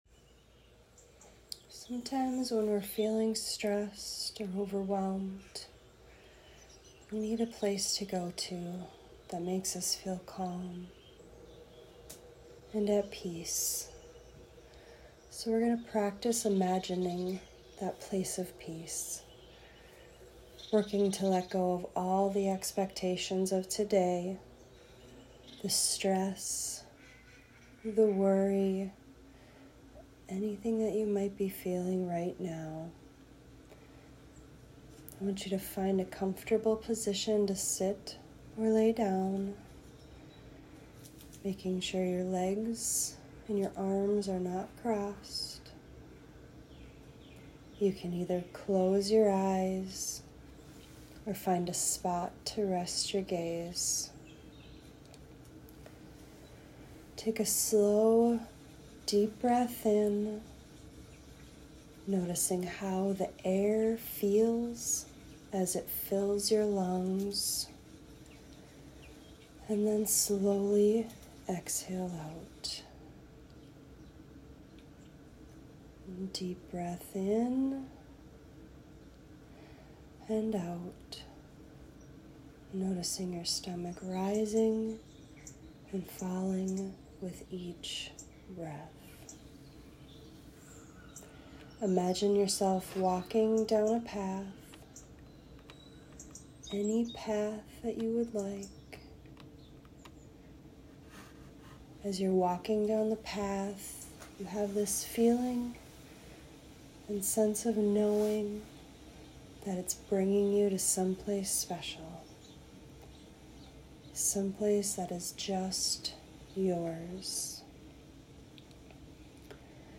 Waterfall Guided Meditation - Wild Tree Wellness
Find peace and let go of stress and worry with this guided nature meditation.
WaterfallRelaxation.m4a